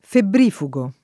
vai all'elenco alfabetico delle voci ingrandisci il carattere 100% rimpicciolisci il carattere stampa invia tramite posta elettronica codividi su Facebook febbrifugo [ febbr & fu g o ; non -f 2g o ] agg. e s. m.; pl. m. -ghi